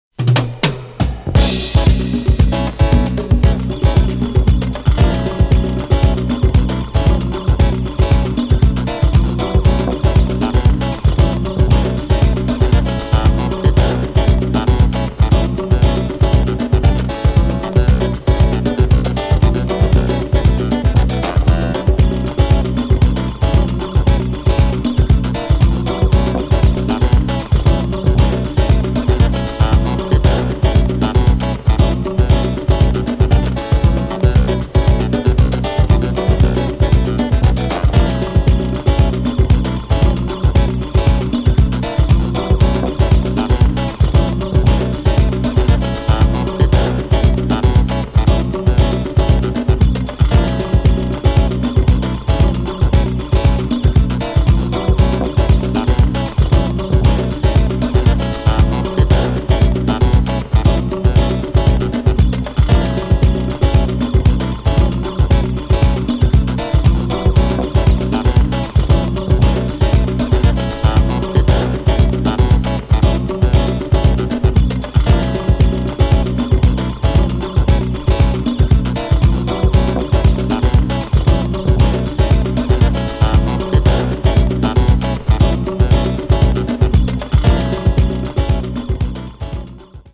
straight from the basement
house music